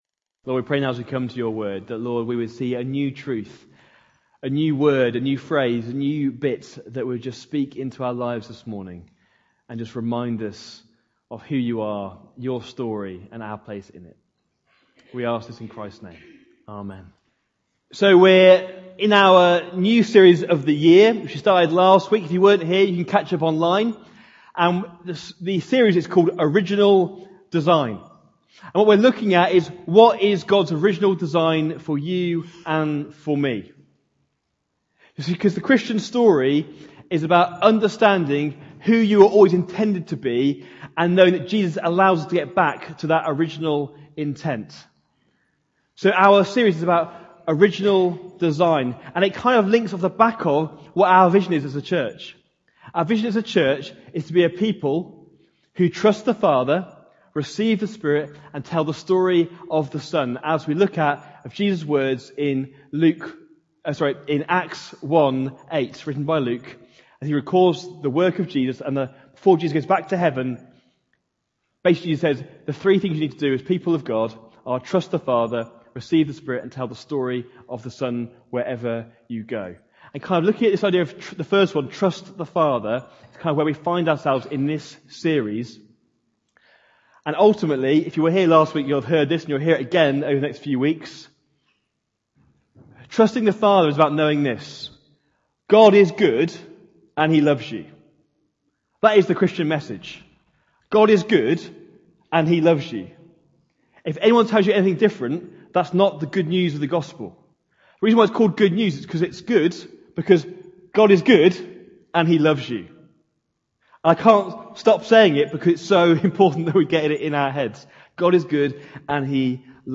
Jan 12, 2020 Original Design Part 2 : Blessed to Bless MP3 SUBSCRIBE on iTunes(Podcast) Notes Discussion Sermons in this Series In This message we look at what it means to reflect God's Glory as we seek to be Faithful to be fruitful.